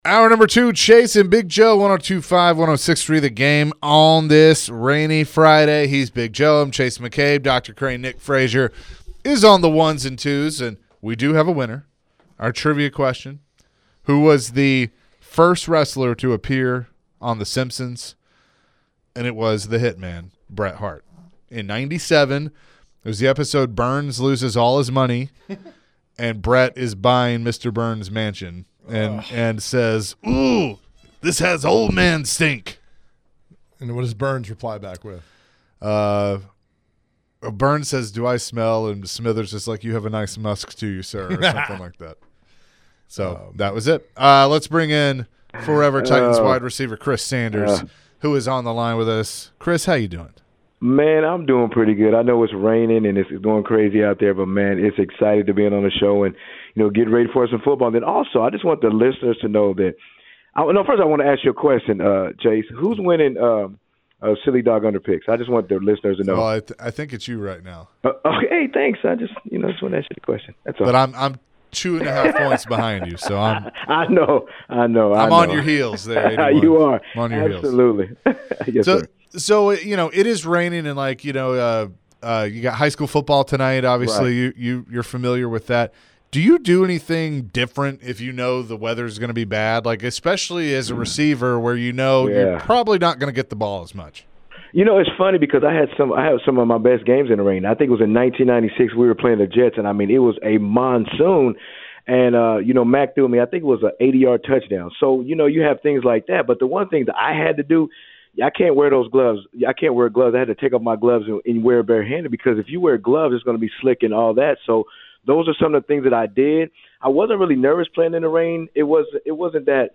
Forever Titans WR Chris Sanders joined the show and shared his thoughts on the Titans recent slump to start the season? Chris later in the conversation mentioned if the Titans go 0-4, fans and coaches will see who the real leaders are on the team.